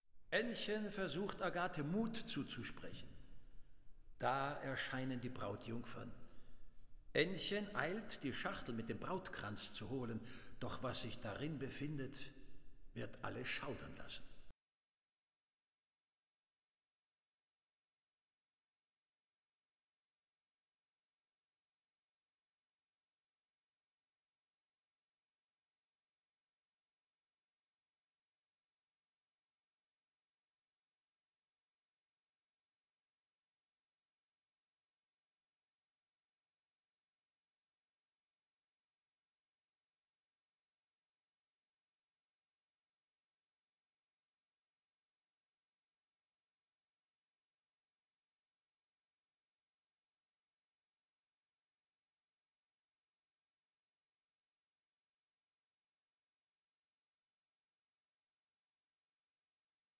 THE GERMAN ROMANTIC OPERA PAR EXCELLENCE